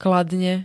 Zvukové nahrávky niektorých slov
bmks-kladne.ogg